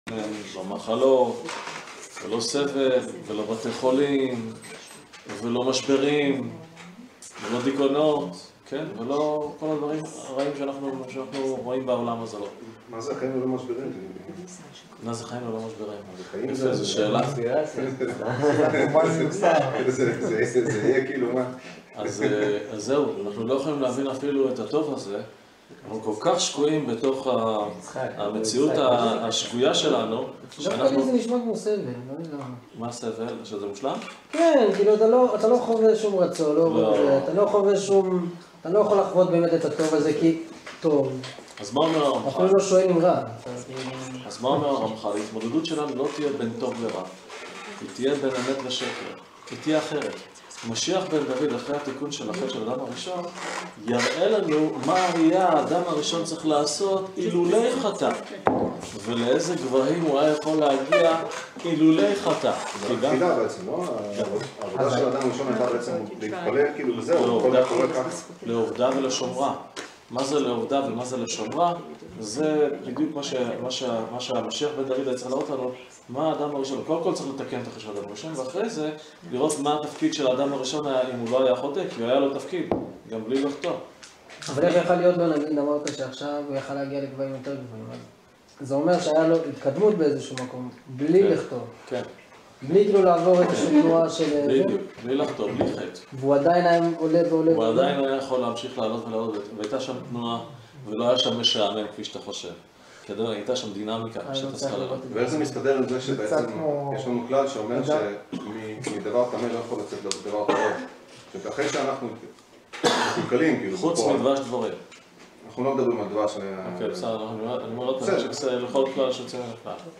הרצאה